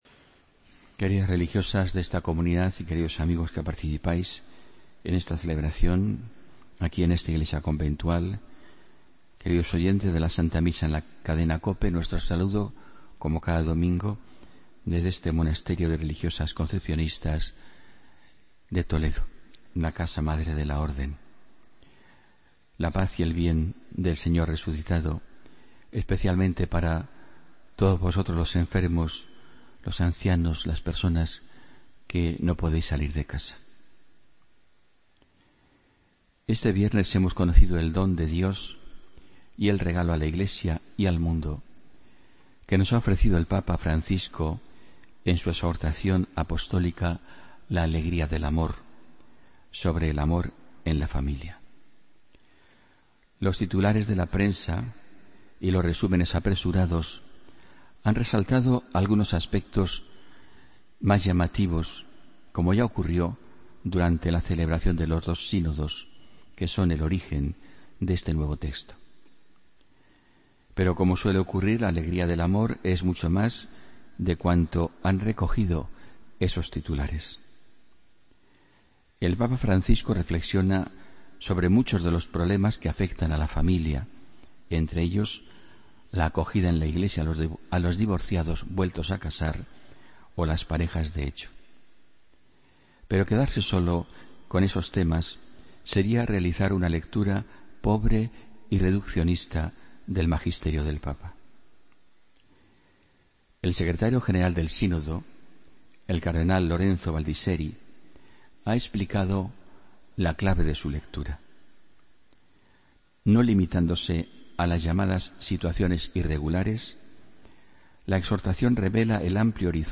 Homilía del domingo 10 de abril de 2016